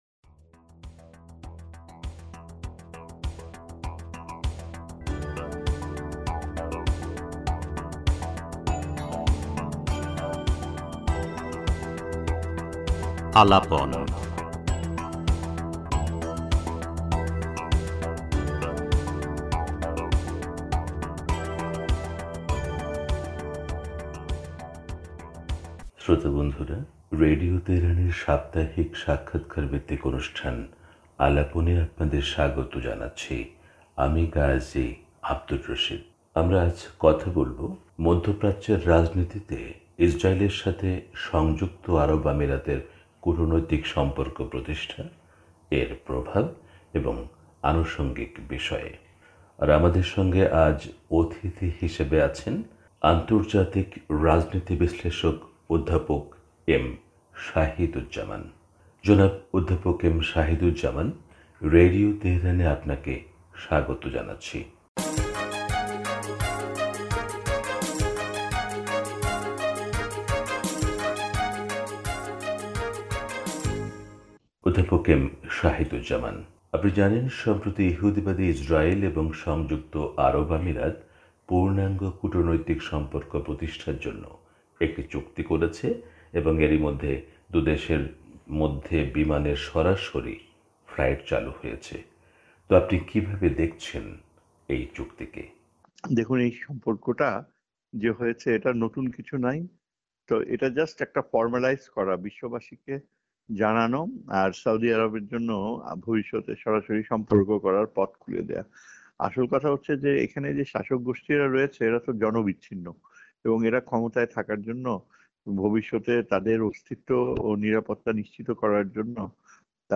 রেডিও তেহরানকে দেয়া বিশেষ সাক্ষাৎকারে সুনির্দিষ্ট করে তিনি বলেছেন, ইসরাইলের এই ধরণের স্বীকৃতি নতুন অস্থিতিশীলতা ও অস্থিরতার দিকে নিয়ে যাবে। আরব বিশ্বের জন্য ভবিষ্যতে এটি সর্বনাশের পথ খুলে দিতে পারে ।
পুরো সাক্ষাৎকারটি তুলে ধরা হলো।